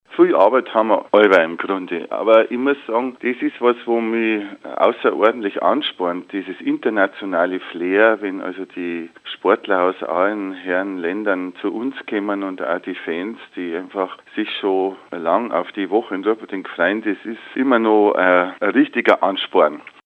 Das motiviert ungemein, sagt der Bürgermeister.